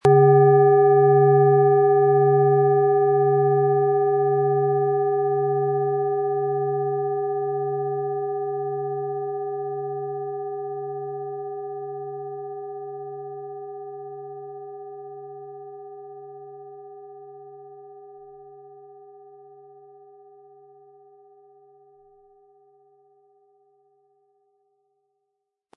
Planetenschale® Stirb- und Werde-Prinzip & Integration neuer Impulse mit Pluto, Ø 17 cm, 600-700 Gramm inkl. Klöppel
Planetenton
Um den Original-Klang genau dieser Schale zu hören, lassen Sie bitte den hinterlegten Sound abspielen.
SchalenformBihar
HerstellungIn Handarbeit getrieben
MaterialBronze